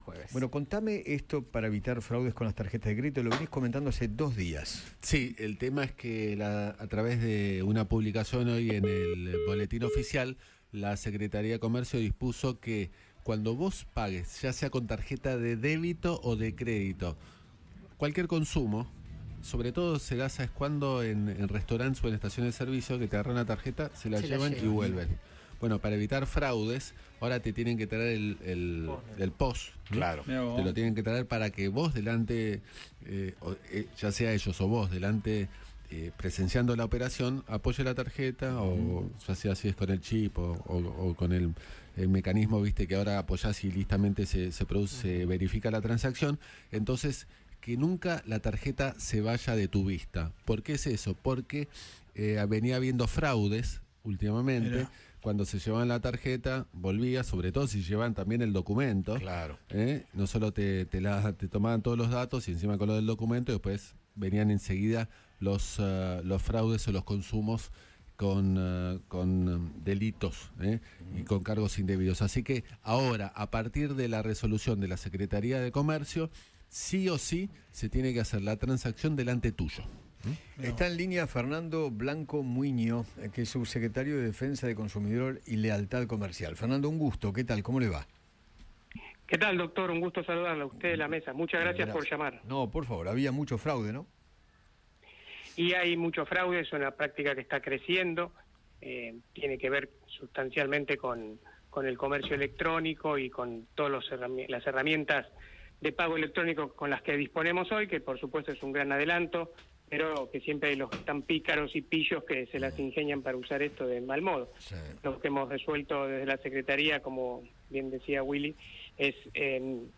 Fernando Blanco Muiño, subsecretario de Defensa del Consumidor y Lealtad Comercial, dialogó con Eduardo Feinmann sobre la nueva disposición del Gobierno para los pagos con tarjetas.